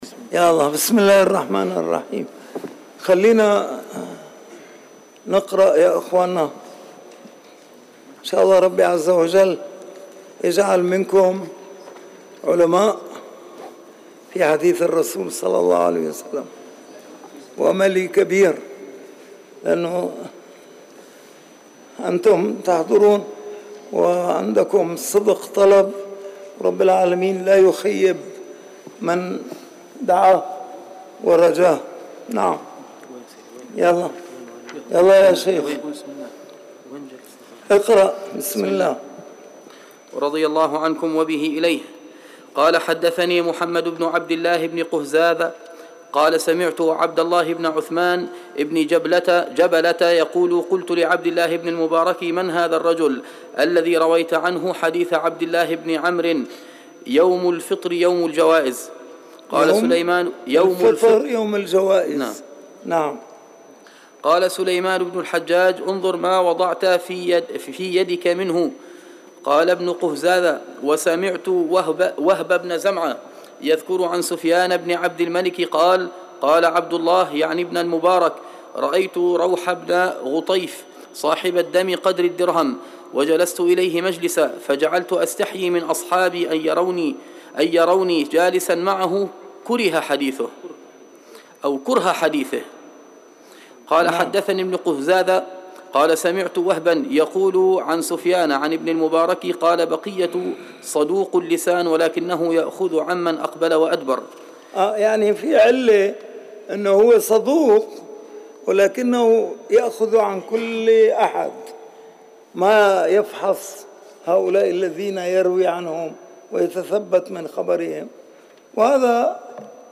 أرشيف الإسلام - ~ أرشيف صوتي لدروس وخطب ومحاضرات الشيخ شعيب الأرناؤوط
تعليقات على مقدمة صحيح الإمام مسلم .الدرس الأول